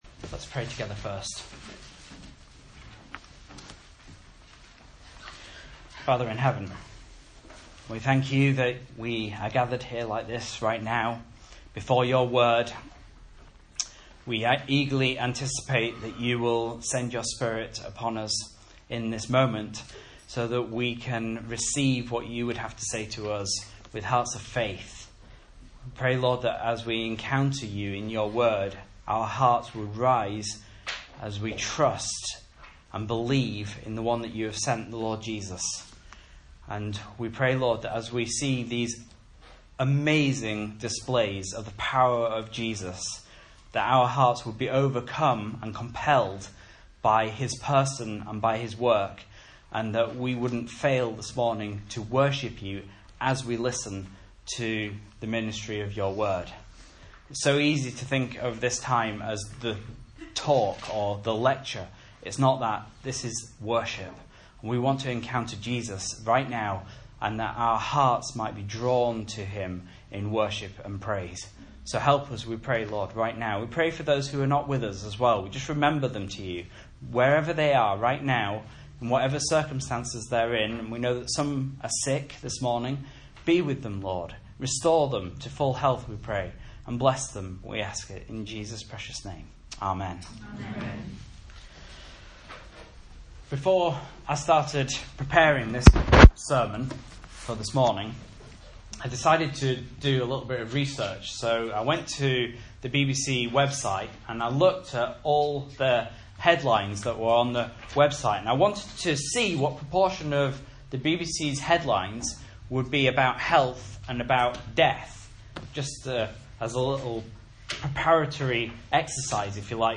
Message Scripture: Mark 5:21-43 | Listen